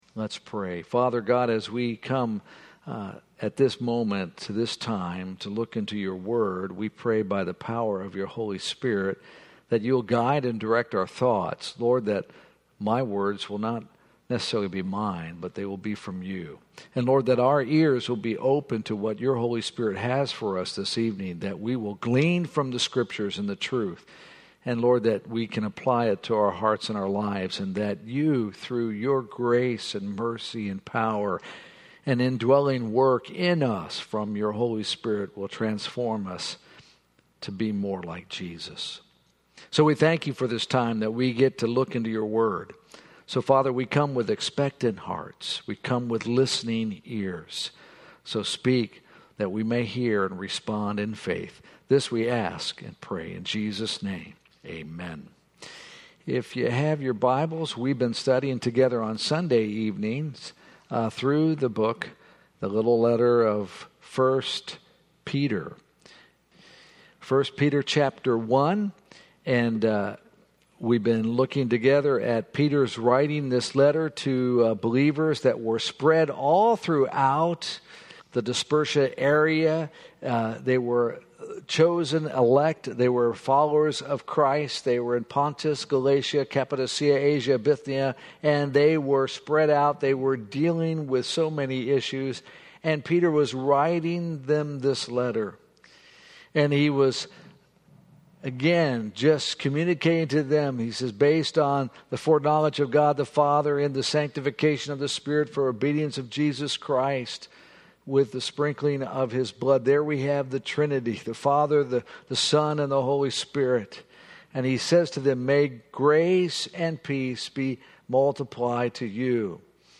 Sermons | Alliance church of Zephyrhills